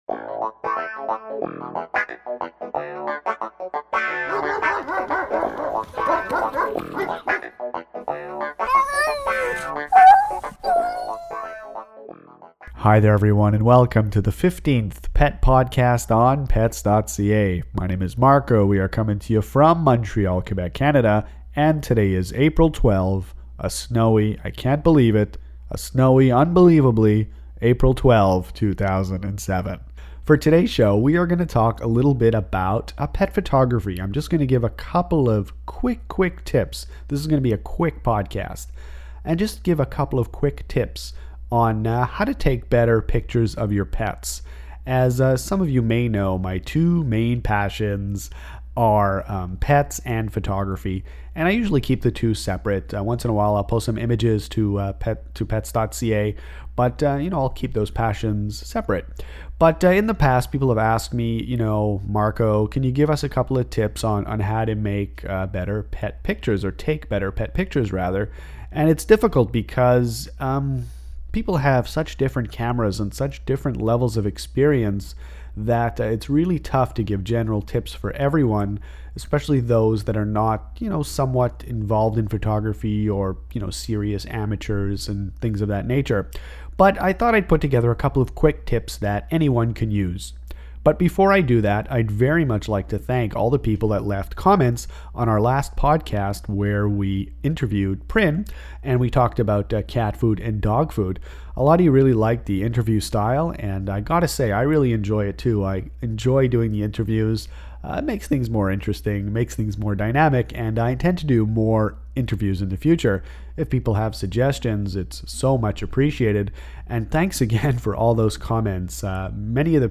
Pet podcast #15 is a short podcast that offers 2 easy photo tips to improve your pet photography regardless of your photography experience or the camera you have.